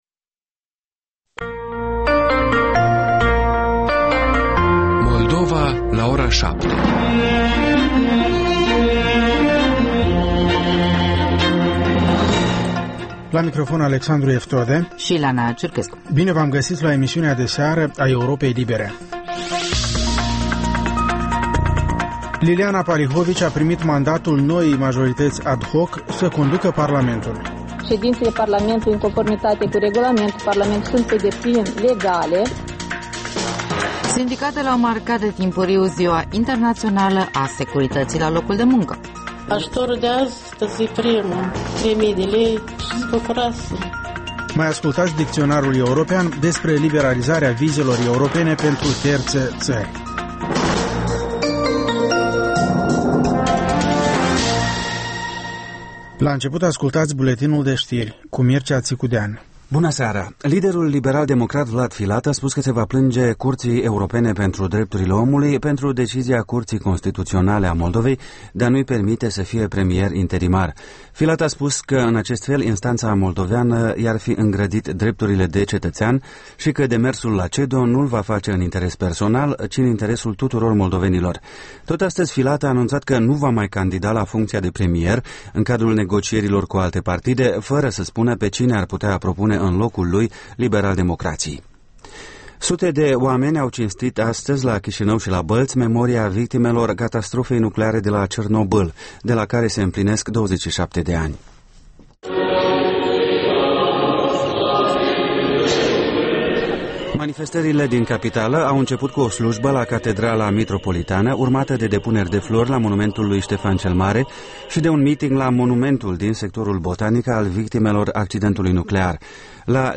Ştiri, interviuri, analize şi comentarii.